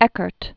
(ĕkərt), J(ohn) Presper 1919-1995.